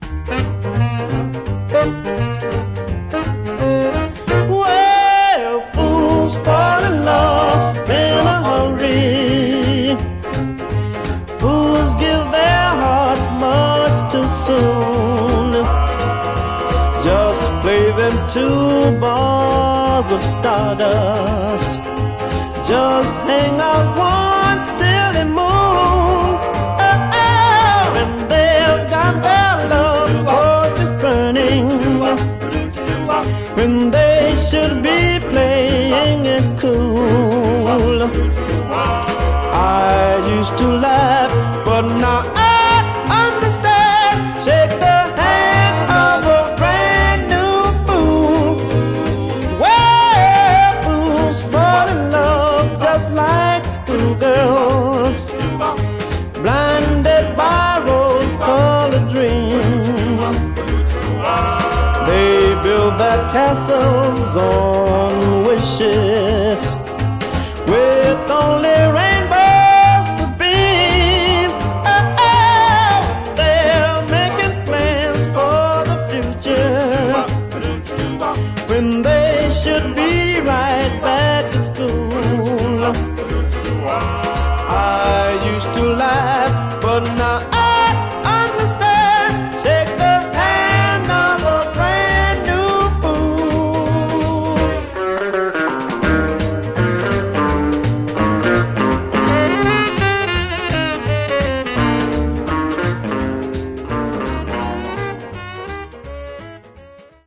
Genre: Vocal Groups (Doo-Wop)